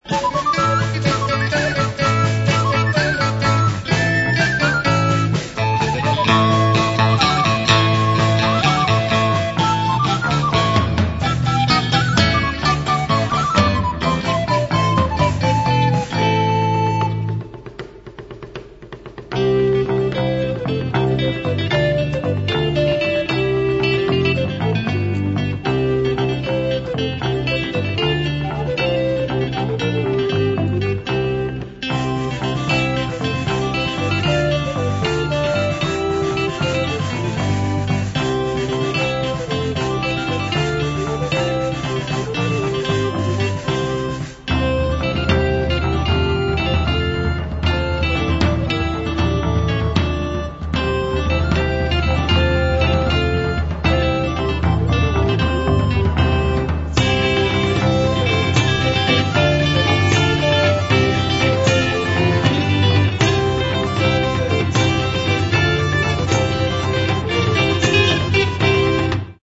Mono, 1:08, 32 Khz, (file size: 276 Kb).